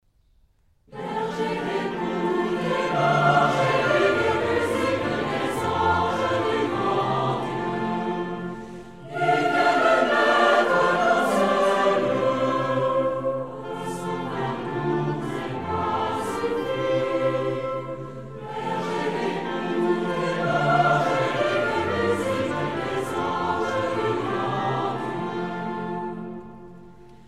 pour choeur a cappella SATB